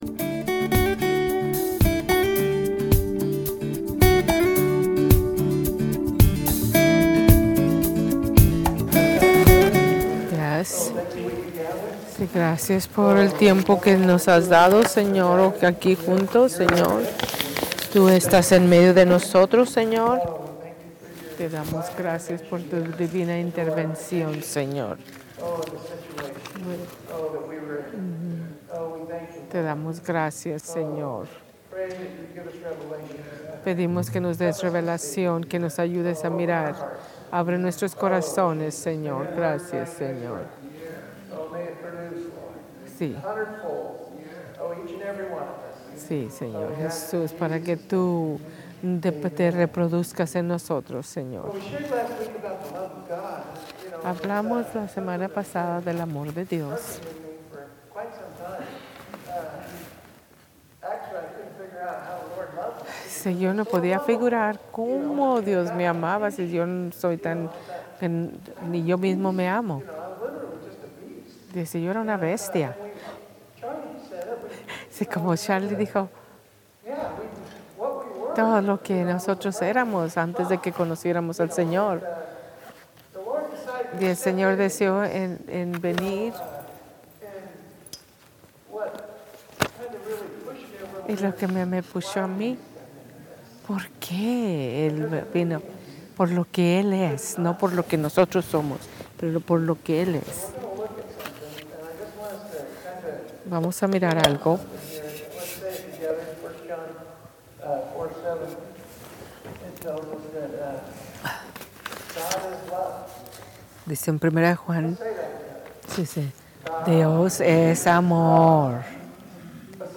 Sermones en Español